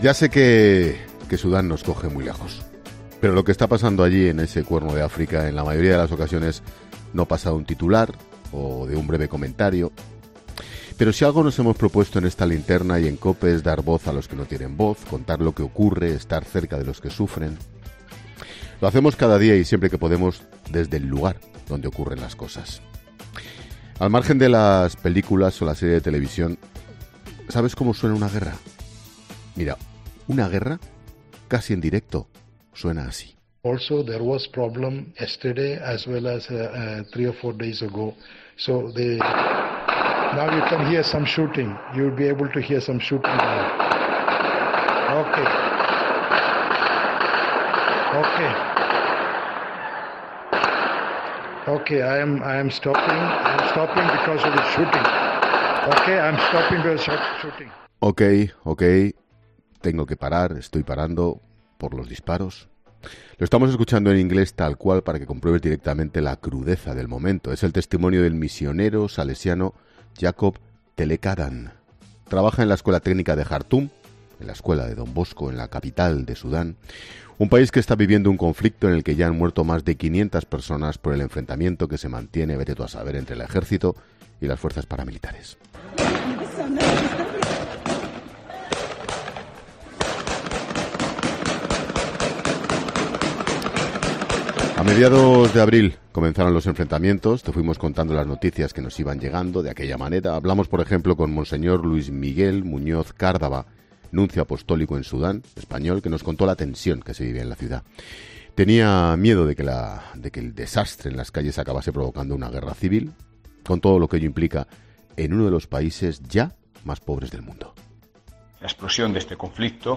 Ángel Expósito explica la historia de la conversación interrumpida por las bombas y los disparos de Sudán
Monólogo de Expósito